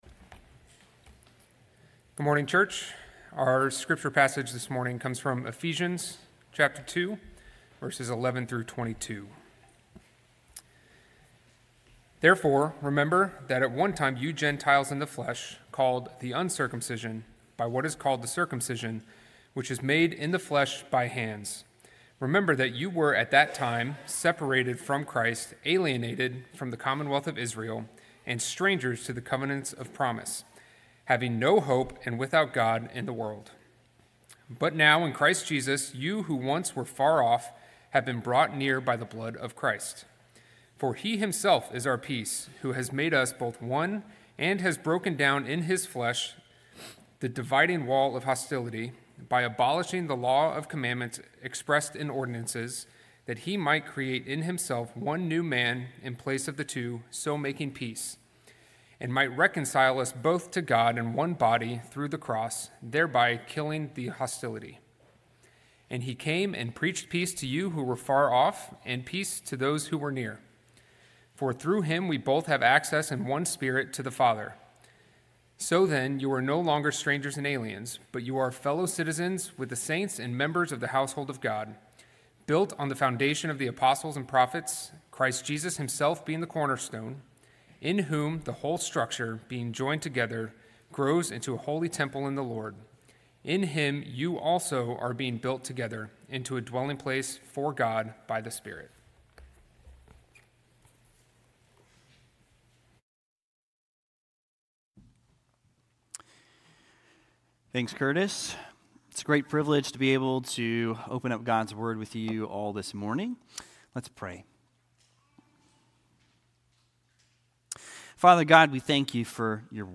sermon8.31.25.mp3